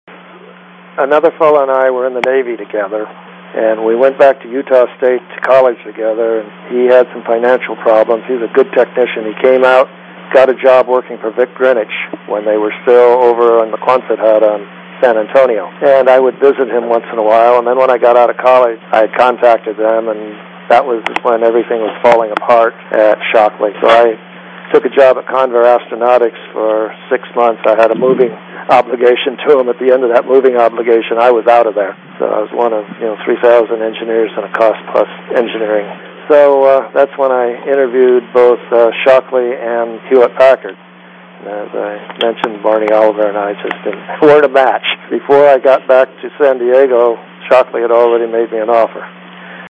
These Audio Links are Excerpts from the August 2003 Interview